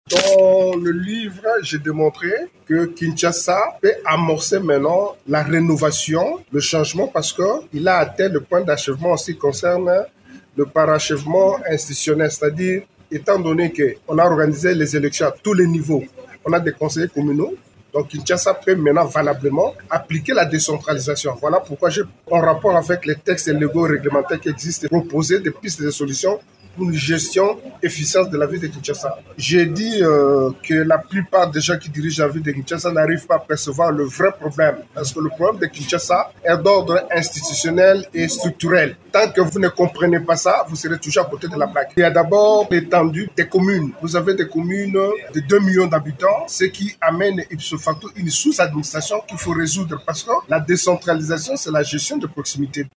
Il revient sur les grandes lignes de son ouvrage au micro